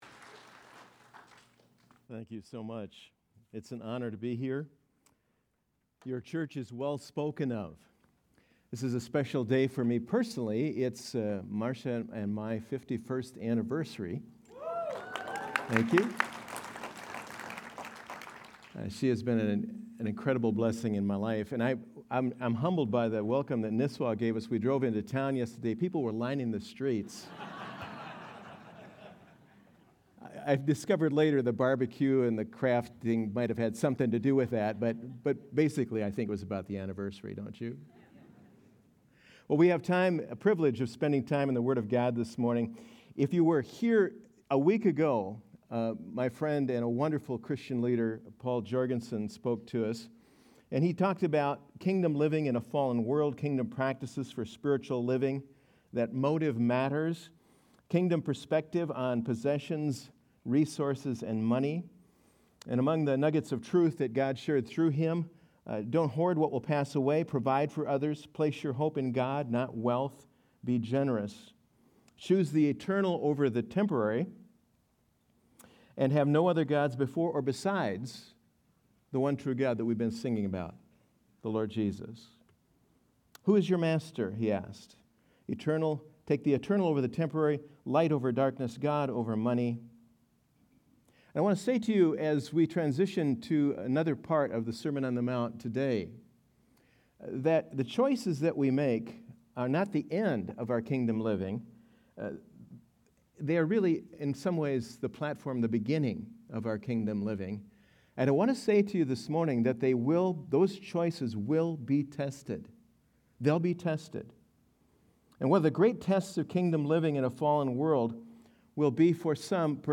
Sunday Sermon: 9-21-25